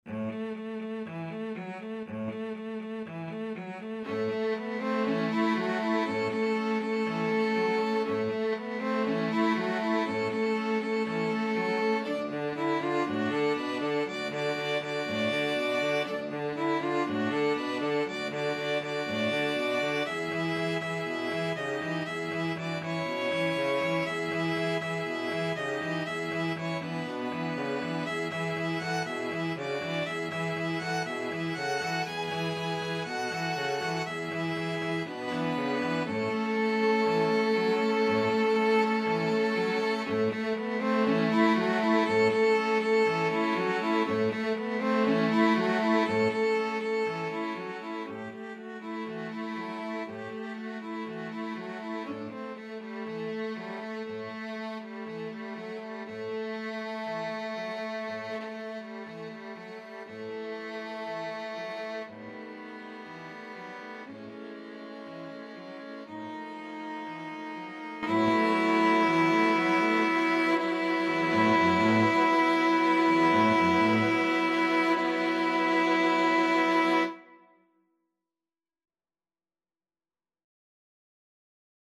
Violin 1Violin 2ViolaCello
4/4 (View more 4/4 Music)
Vivo
Jazz (View more Jazz String Quartet Music)
Rock and pop (View more Rock and pop String Quartet Music)